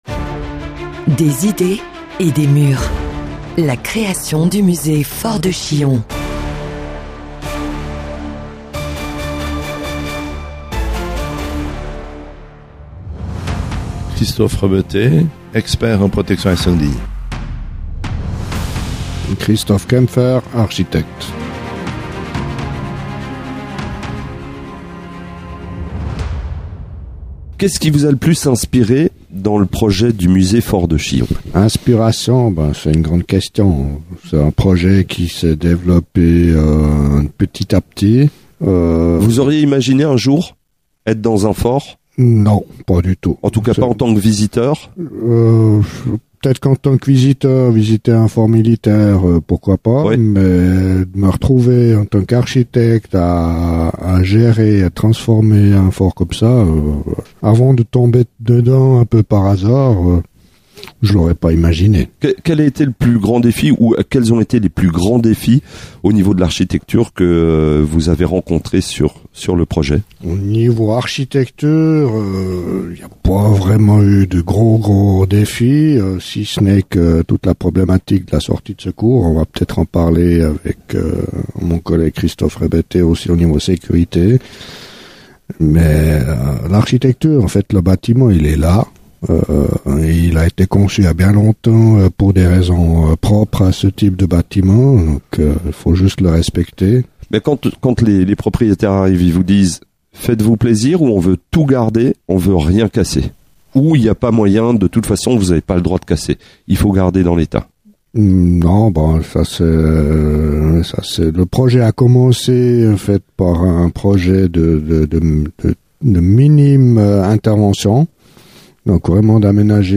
Une immersion auditive avec des experts